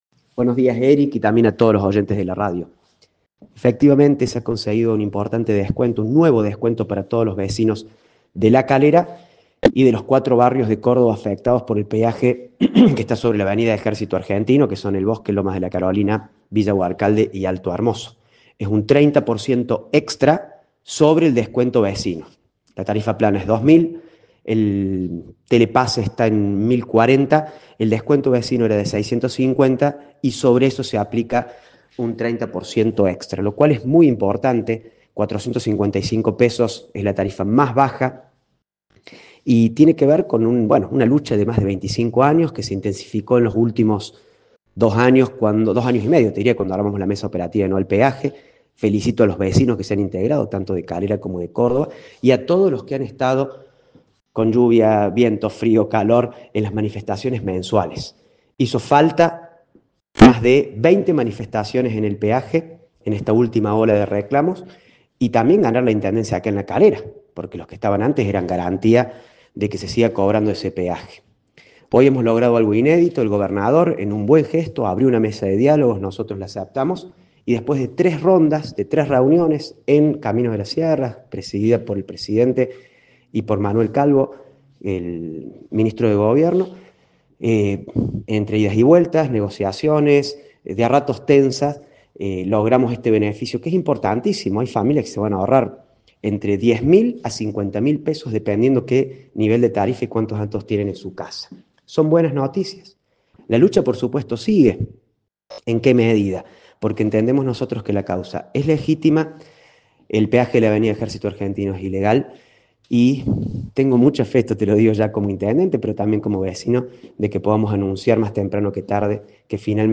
AUDIO DE FERNANDO RAMBALDI, INTENDENTE DE LA CALERA